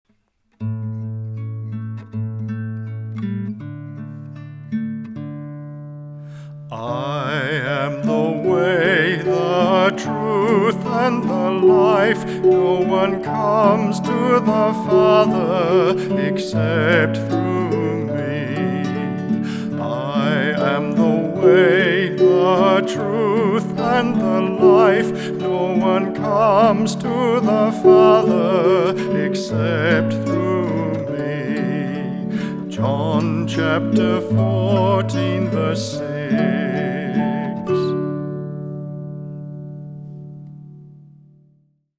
Vocalist
Keyboard
Guitar
Harp